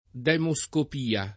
demoscopia [ d H mo S kop & a ] s. f.